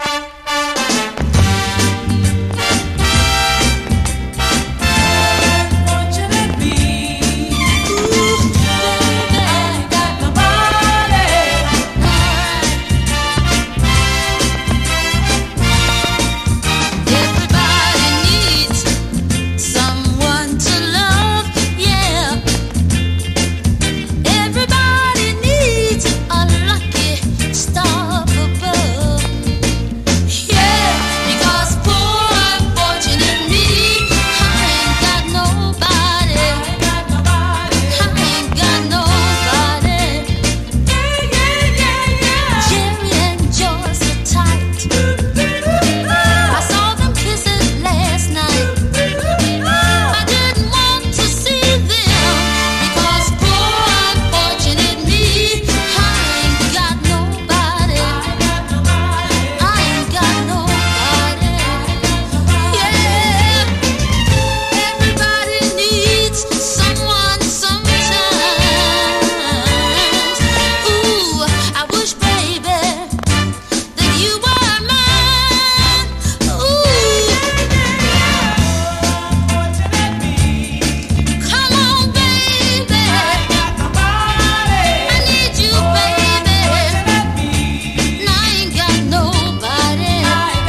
シンシナティ出身のガールズ・トリオ。